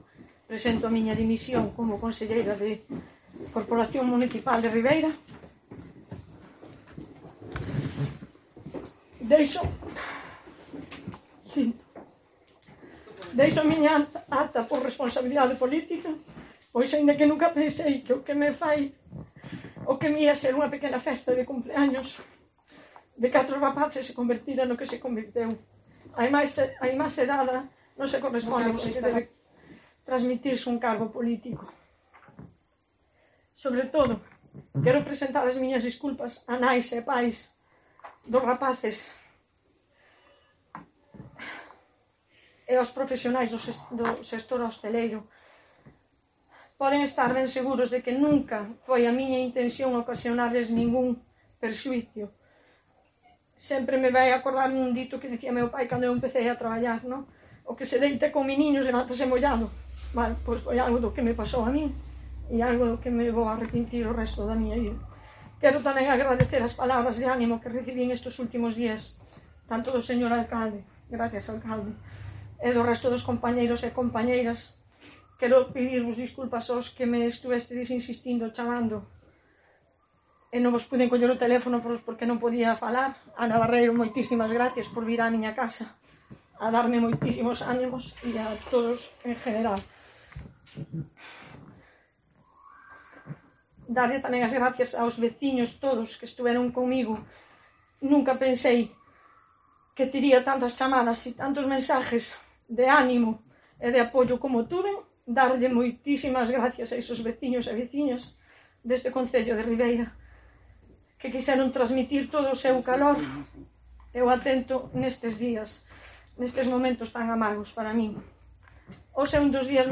Escucha la intervención completa de Ana Isabel Ruiz Reiriz, presentando su dimisión como edil de Ribeira